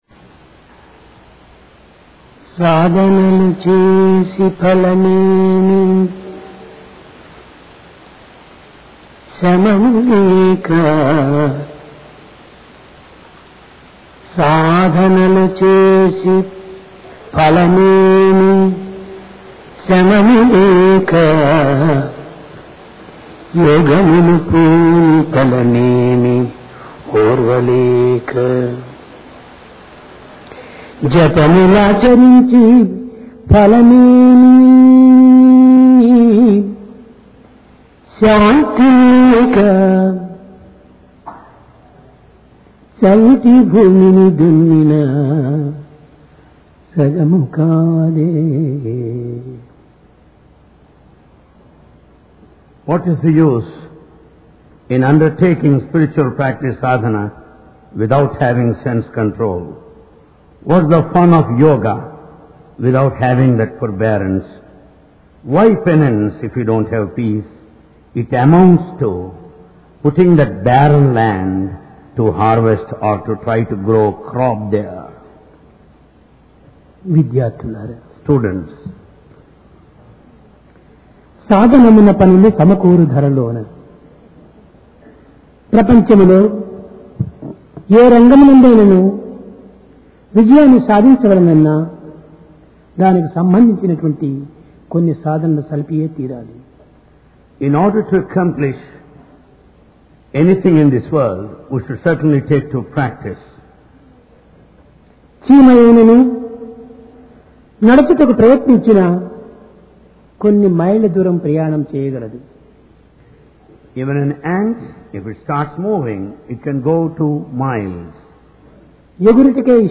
Occasion: Divine Discourse Place: Prashanti Nilayam